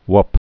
(wŭp, hwŭp, wp, hwp)